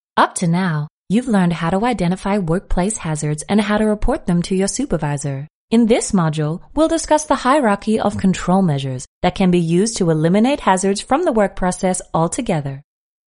Female
Warm, expressive and naturally engaging, with a clear, bright tone that carries emotional nuance. I specialise in a smooth, conversational delivery that feels authentic and approachable, while still offering the clarity and control needed for professional narration.
E-Learning
Usa Accent E-Learning